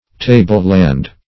Table-land \Ta"ble-land`\, n.